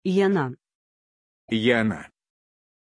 Pronunciation of Jonah
pronunciation-jonah-ru.mp3